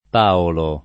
p#olo] pers. m. — con p‑ minusc. come nome di moneta (s. m.)